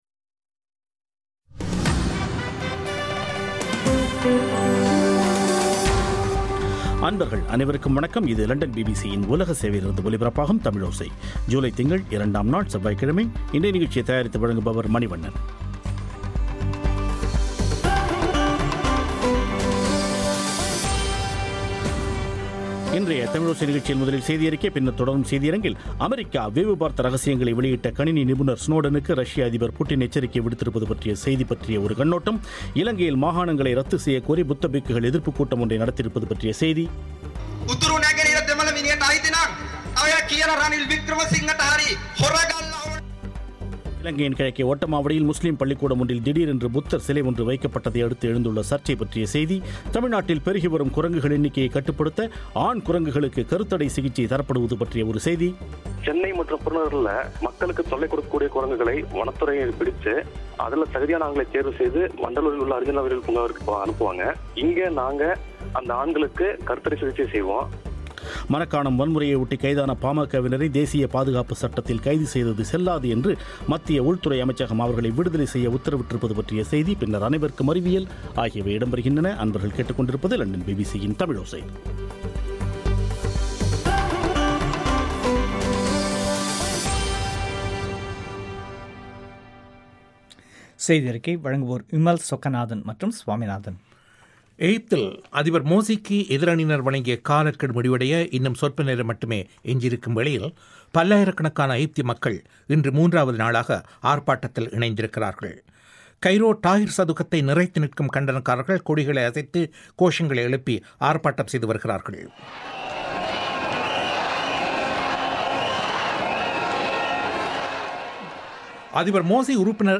இன்றைய தமிழோசை நிகழ்ச்சியில் முதலில் செய்தி அறிக்கை பின்னர் தொடரும் செய்தி அரங்கில்